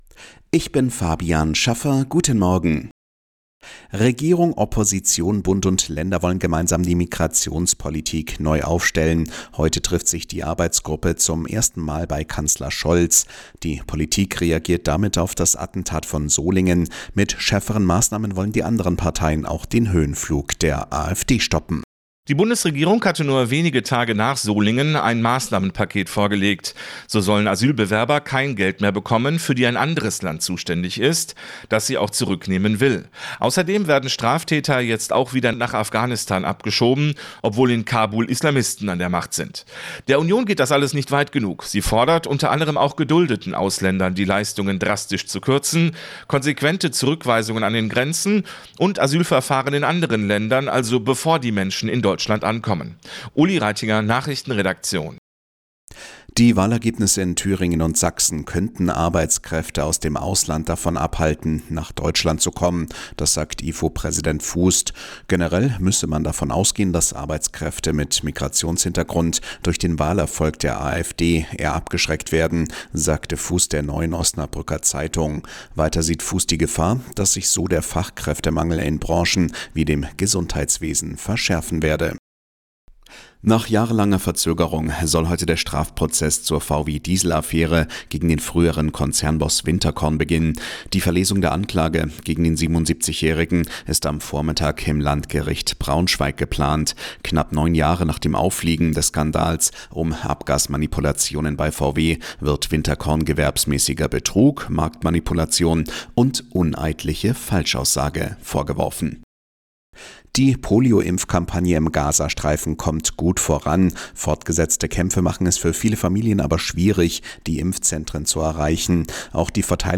Die Arabella Nachrichten vom Dienstag, 3.09.2024 um 04:59 Uhr - 03.09.2024